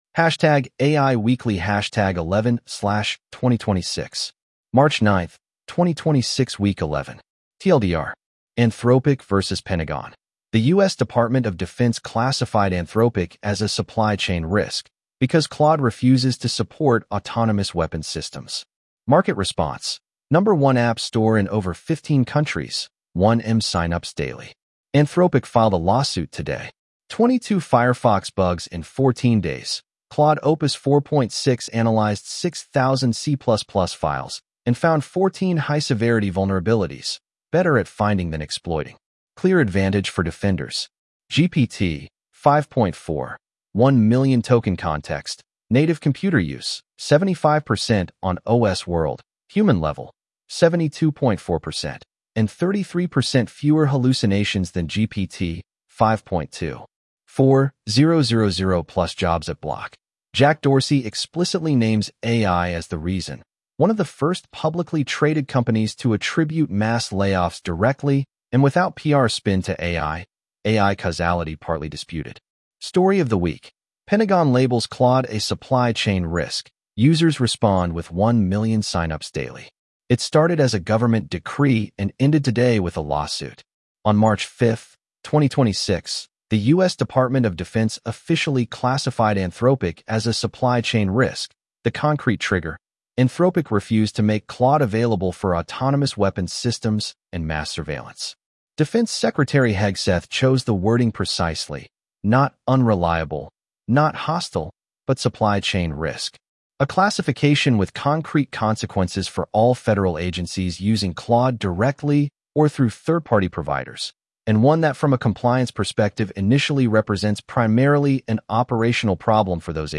Read aloud with edge-tts (en-US-AndrewNeural)